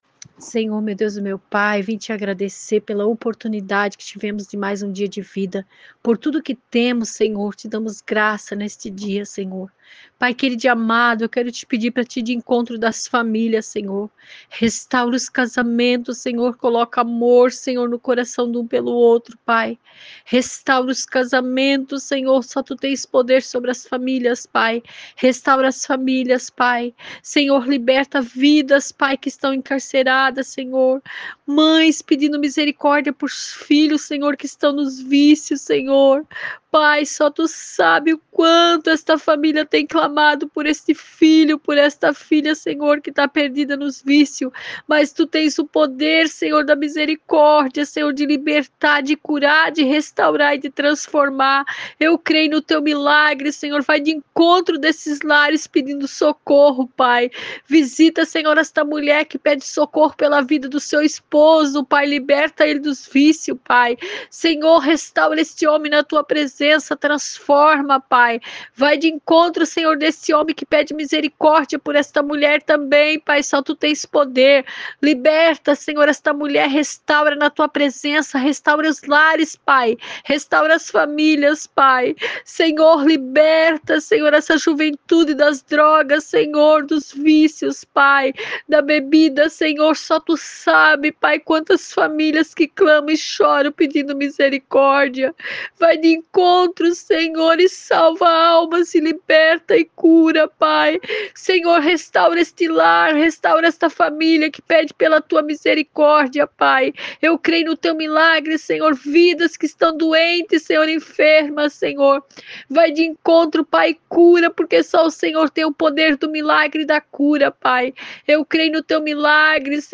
ORAÇÃO